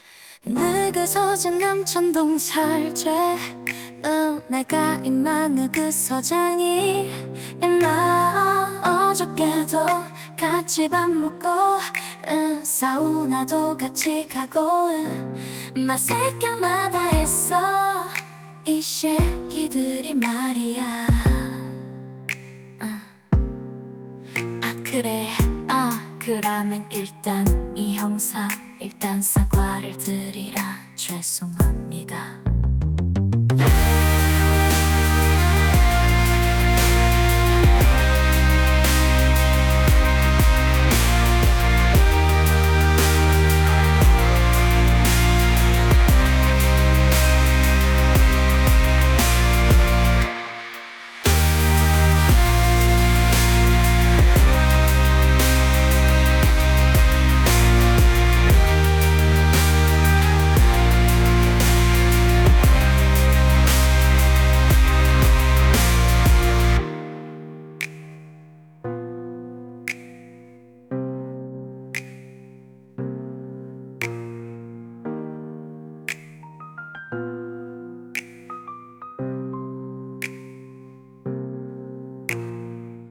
여자 Kpop 아이돌 버전 <느그 서장 남천동 살제>